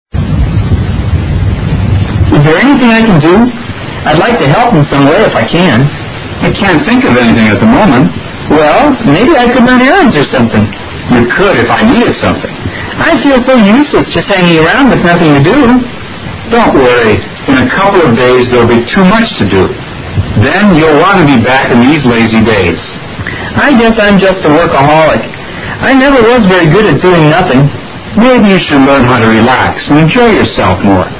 Dialogue 15